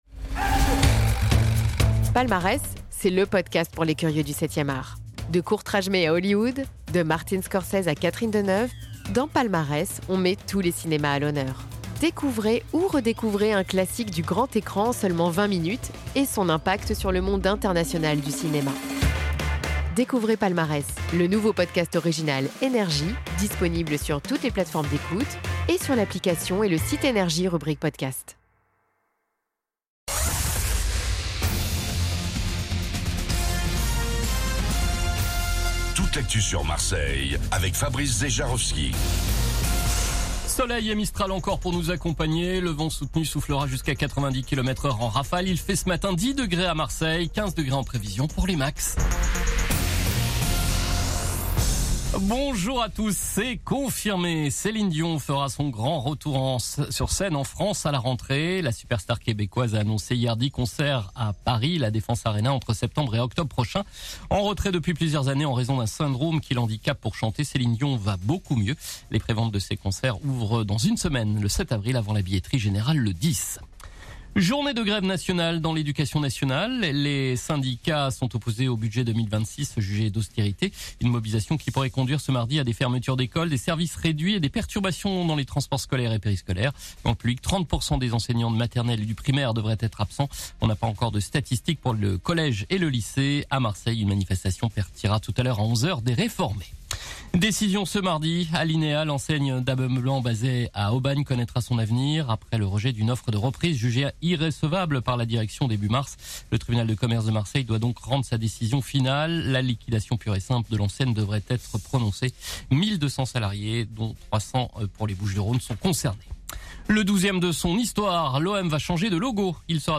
Réécoutez vos INFOS, METEO et TRAFIC de NRJ MARSEILLE du mardi 31 mars 2026 à 07h00